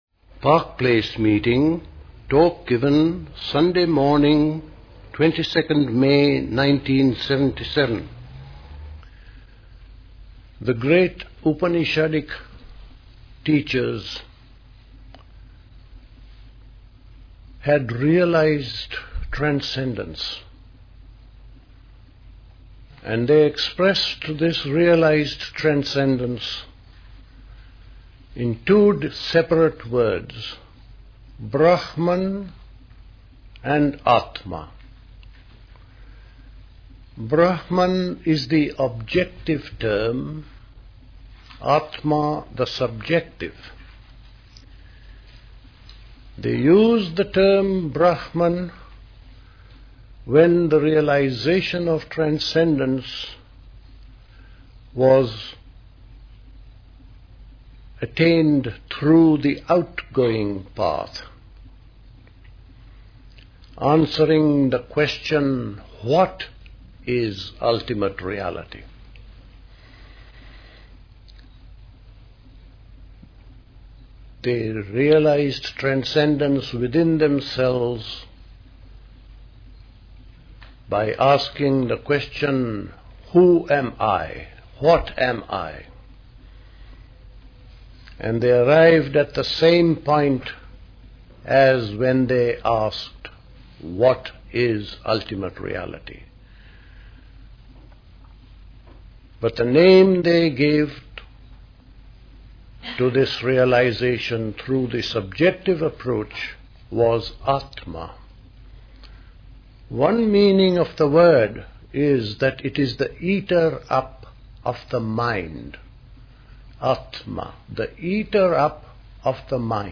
A talk
at Park Place Pastoral Centre, Wickham, Hampshire